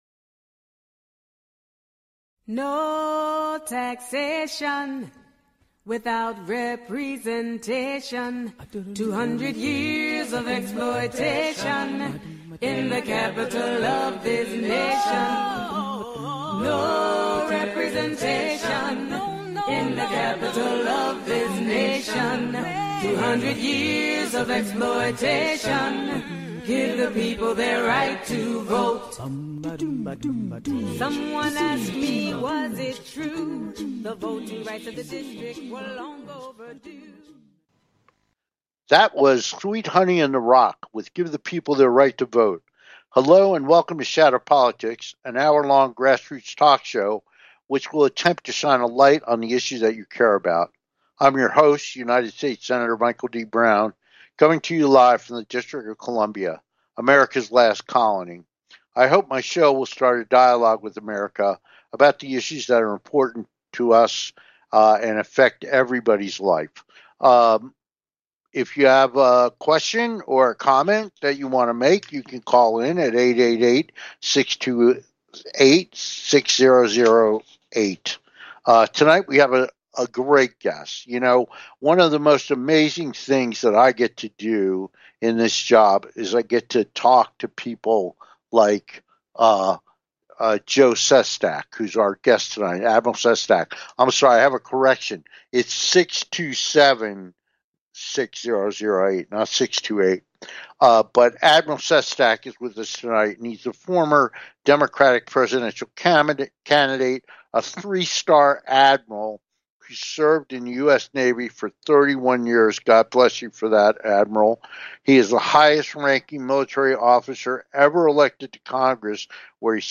Guest, Joe Sestak, Democratic 2020 Presidential Candidate